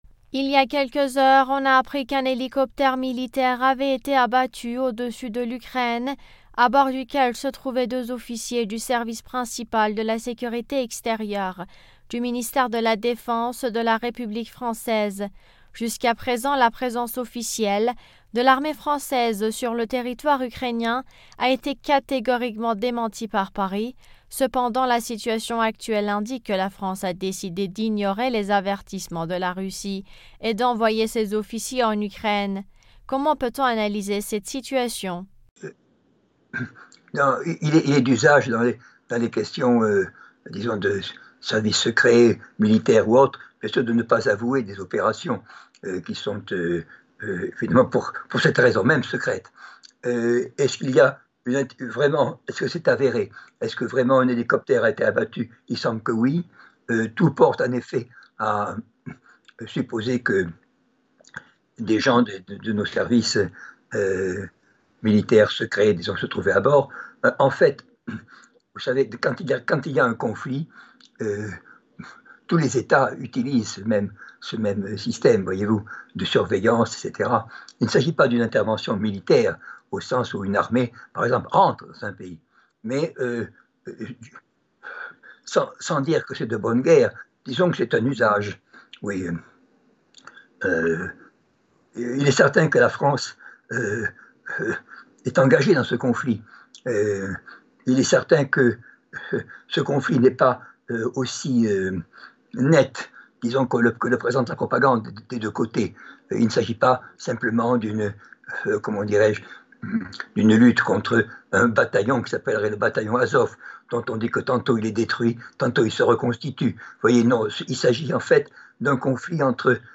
politologue, s’exprime sur le sujet.